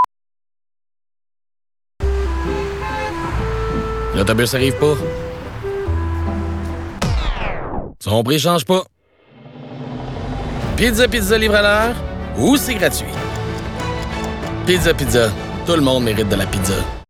Voix annonceur – Pizza pizza
confiante, familière, touche d’humour
Pizza-Pizza_Delivery-guarentee_Bus_FR_WEB.mp3